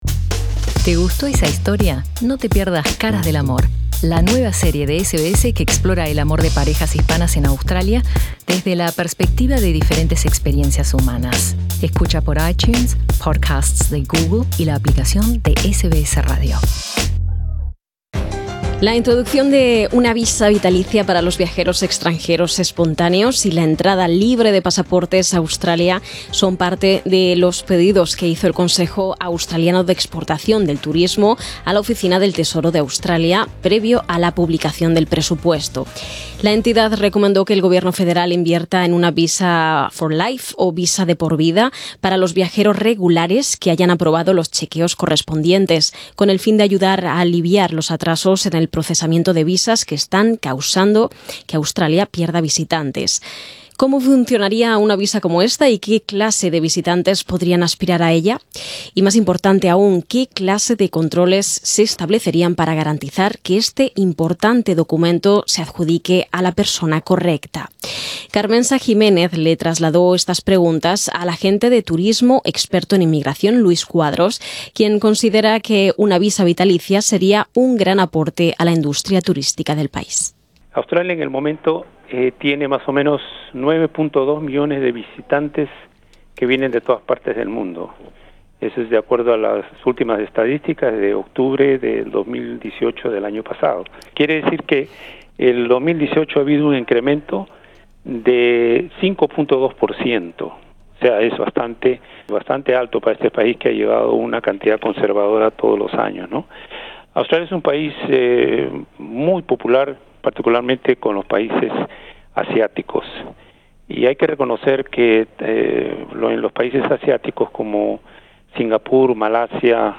Escucha arriba en nuestro podcast la entrevista al agente de turismo experto en inmigración